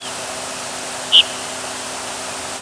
presumed Rose-breasted Grosbeak nocturnal flight calls
Nocturnal flight call sequences: